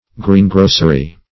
greengrocery - definition of greengrocery - synonyms, pronunciation, spelling from Free Dictionary